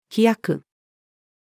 飛躍-female.mp3